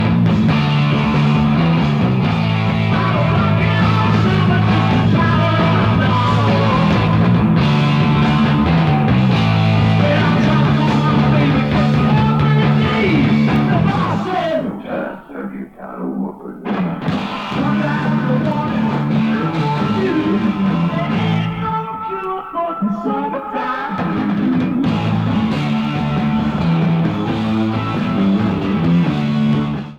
Live versions from Paris France, September 9, 1972.
Sound Samples/Track Listing (All Tracks In Mono)